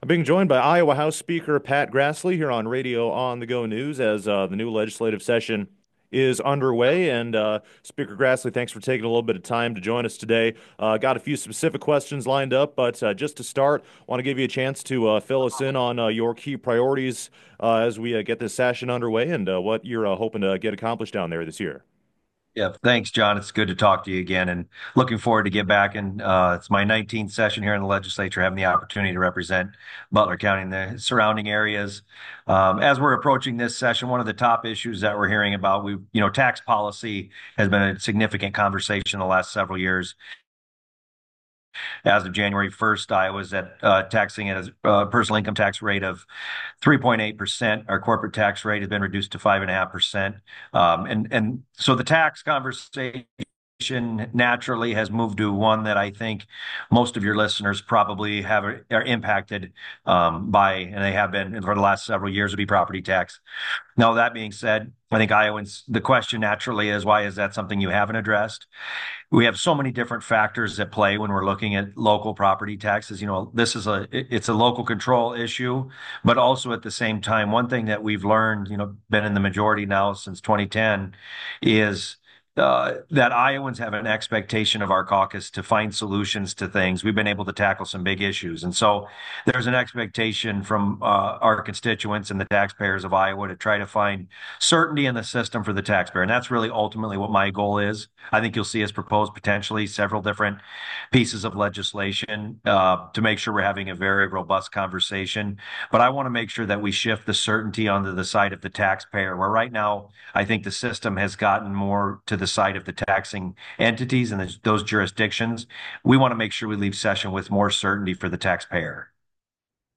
Interview with Grassley: